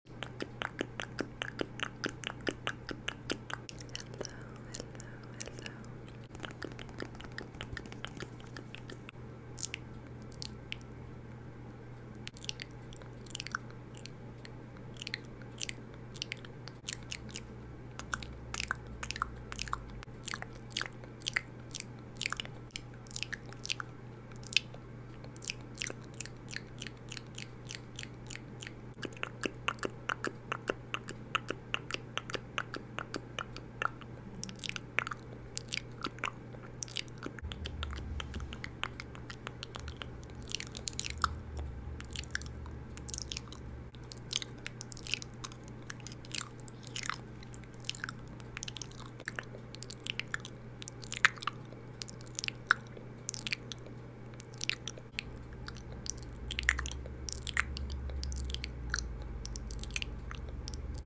Asmr | Assortment Of Mouth Sound Effects Free Download
asmr | assortment of mouth sounds✨ (lipgloss application, tongue clicks, spoolie nibbling)